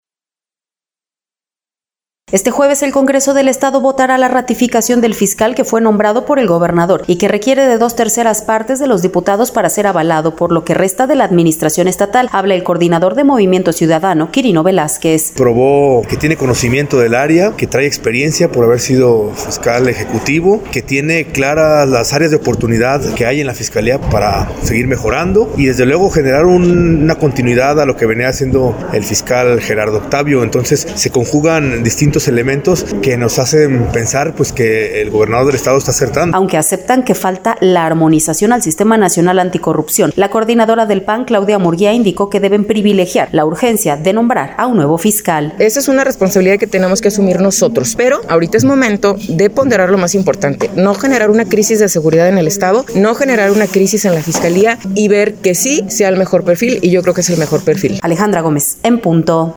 Este jueves el Congreso del Estado, votará la ratificación del fiscal que fue nombrado por el gobernador y que requiere de dos terceras partes de los diputados para ser avalado, por lo que resta de la administración estatal. Habla el Coordinador de Movimiento Ciudadano, Quirino Velázquez.
Aunque aceptan que falta la armonización al Sistema Nacional Anticorrupción, la Coordinadora del PAN, Claudia Murgía, Indicó que deben privilegiar la urgencia, de nombrar a un nuevo fiscal.